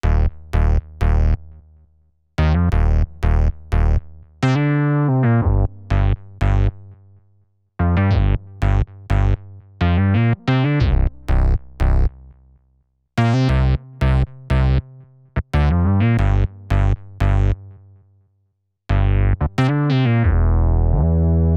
03 bass.wav